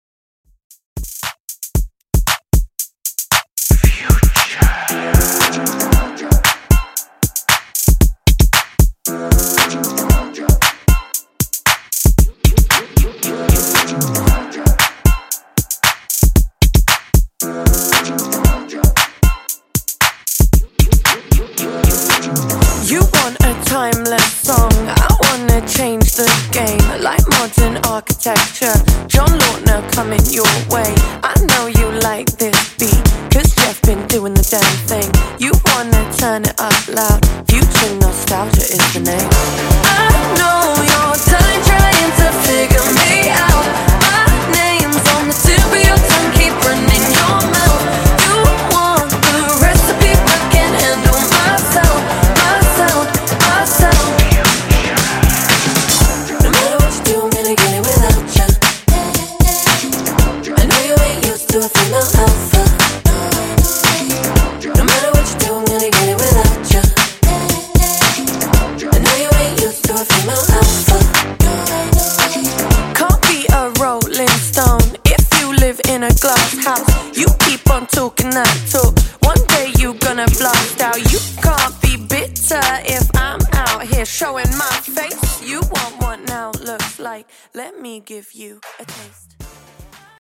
2000's , OLD SCHOOL HIPHOP , REGGAETON